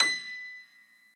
admin-fishpot/b_piano1_v100l1o7b.ogg at 595079ff8b5da54878cbcc810fdb542aacb3becd